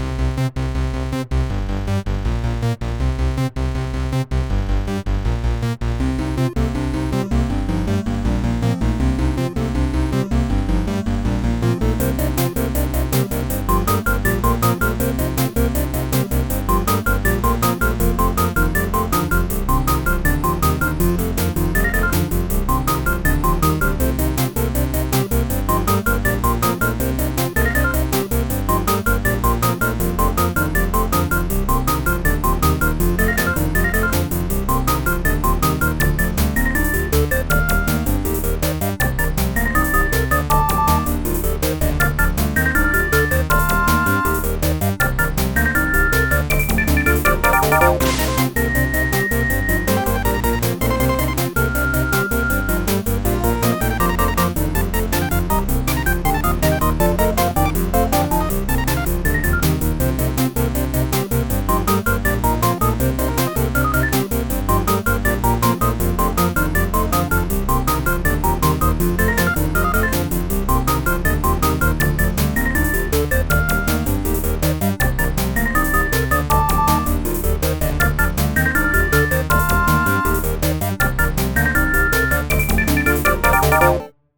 Some kind of electronic music by a human pretending to be an AI imitating human songs before AI actually did that. Retrofuturism in a song, perhaps.
Alternate version has all the sub-bass.
canary-all-the-sub-bass.ogg